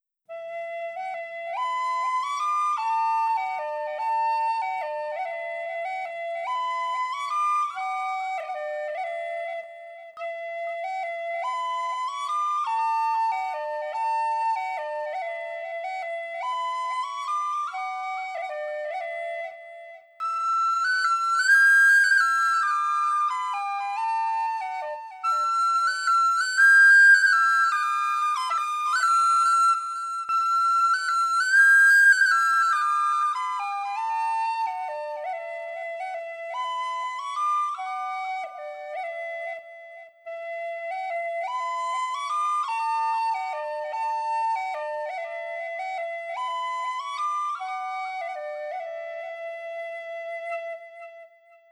Arran Boat Song On the Tin Whistle
I used the software to remove the sounds of me taking a breath, and I added an echo to the track.
And it’s an air, so I’m probably playing it faster than it should be — but I don’t care. I’ve tried to add what players called ornamentation (basically, fancier fingerwork and techniques beyond the original notes — I don’t know a lot, so be kind).
ce85a-arran-boat-song-less-echo-1.wav